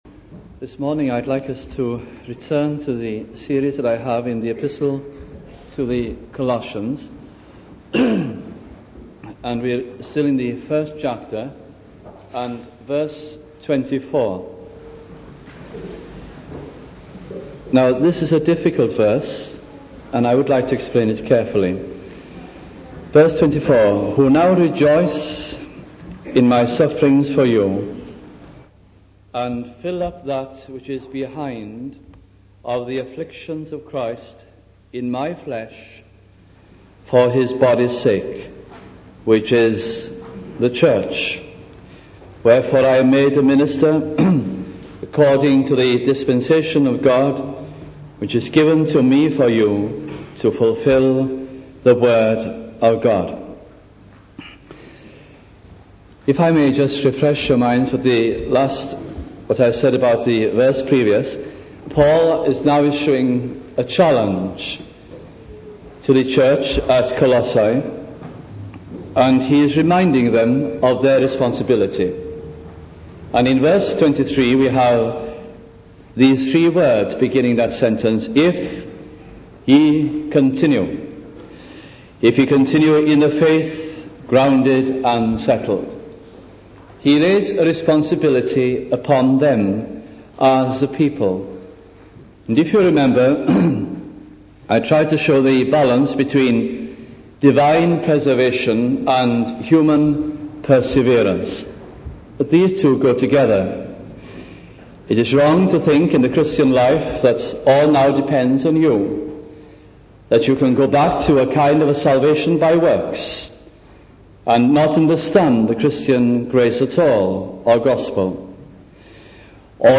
» Colossians Series 1973 » Please note that due to missing parts of the historic audit of recordings this series is incomplete » We also regret that a few sermons in this series do not meet the Trust's expectations of the best sound quality.